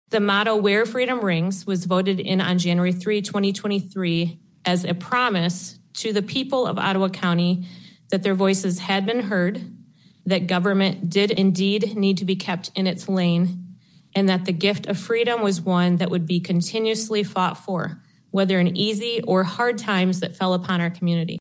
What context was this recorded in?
During Tuesday night’s monthly Board of Commissioners business meeting at the Fillmore Street complex in West Olive, a proposal to, “approve the elimination of the Ottawa County motto of ‘Where Freedom Rings'” passed by a 7-4 margin.